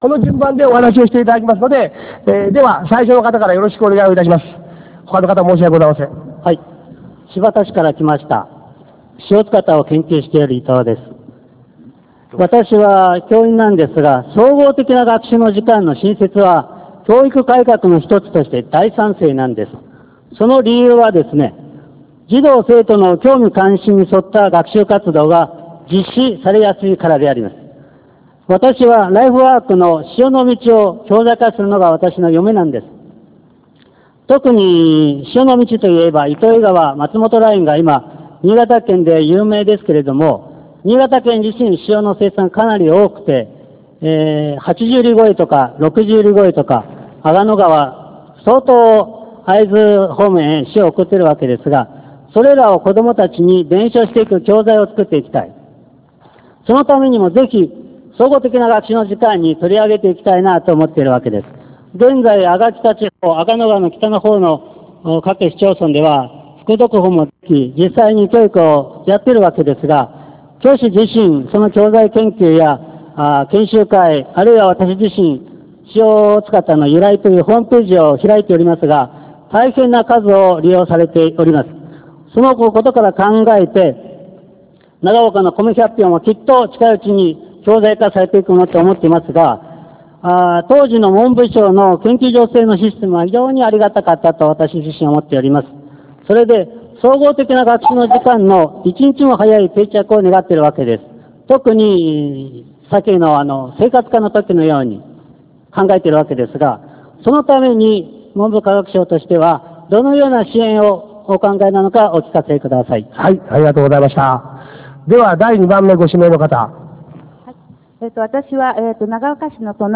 各種講演会の様子
タウンミーティング１(長岡市)